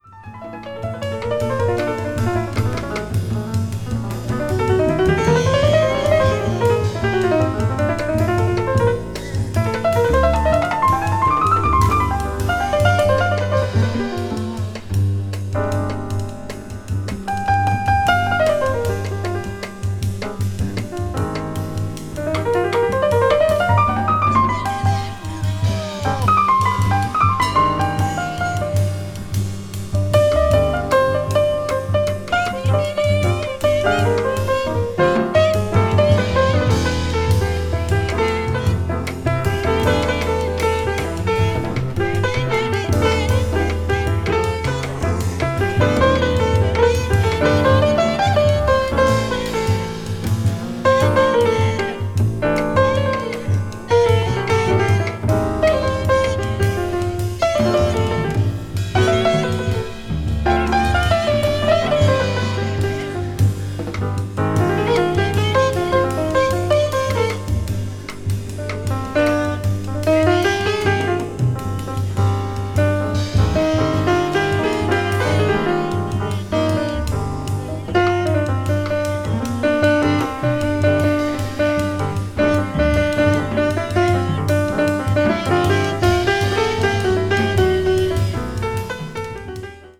media : EX/EX(some slightly noises.)
contemporary jazz   deep jazz   spiritual jazz